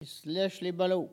Localisation Bouin
Catégorie Locution